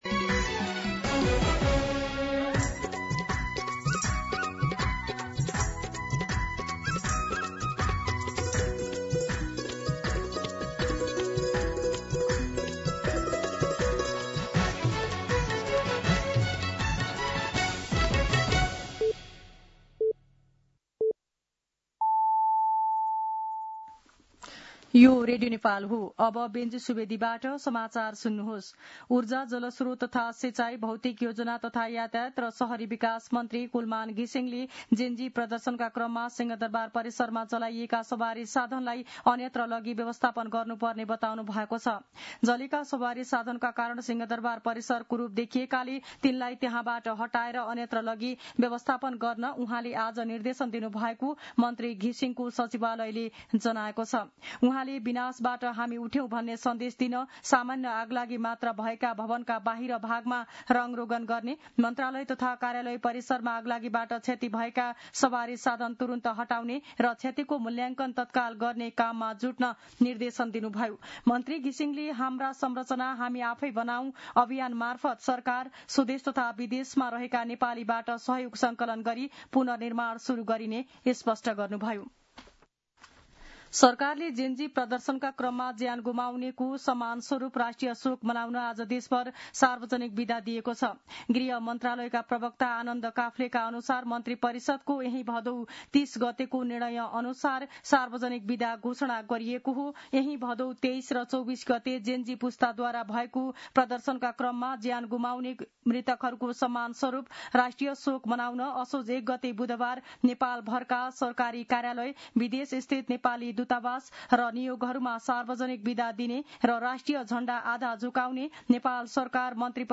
दिउँसो १ बजेको नेपाली समाचार : १ असोज , २०८२
1pm-News-4.mp3